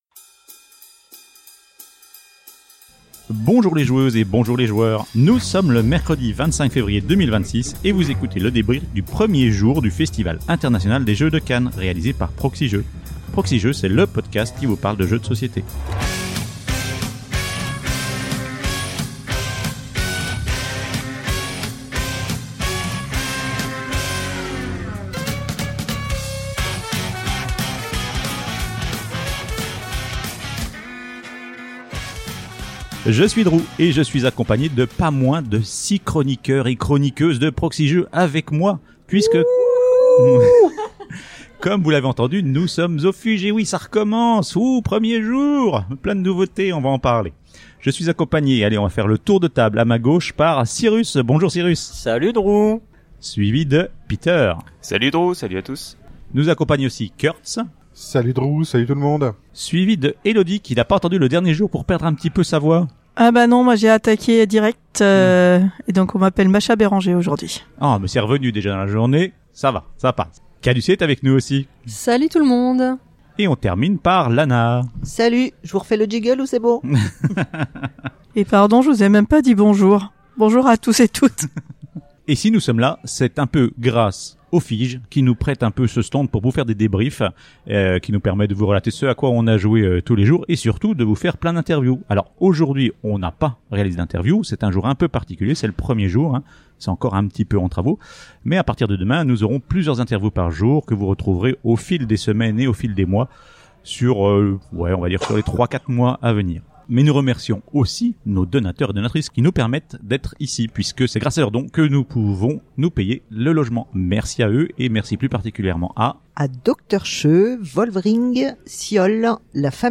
FIJ 2026, mercredi, écoutez le ressenti à chaud de la délégation de Proxi-Jeux présente au Festival International des Jeux de Cannes 2026.